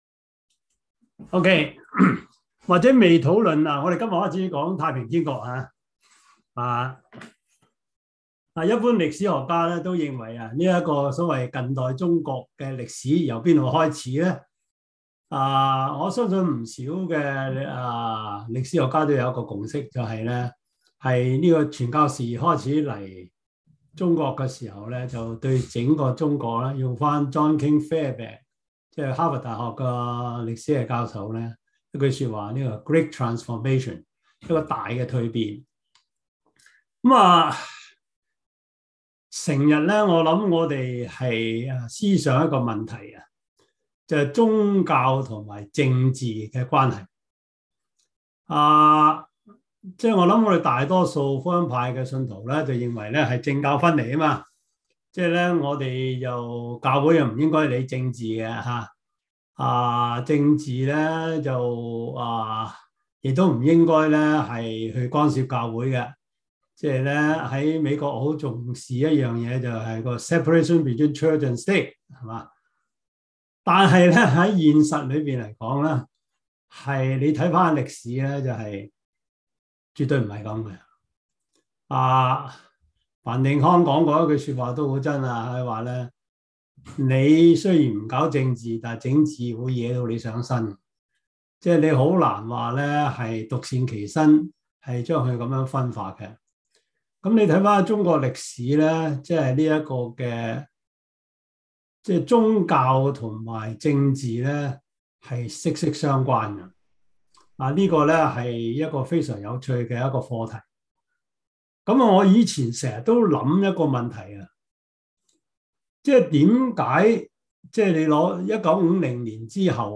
教會歷史 Service Type: 中文主日學 Preacher